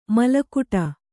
♪ malakuṭa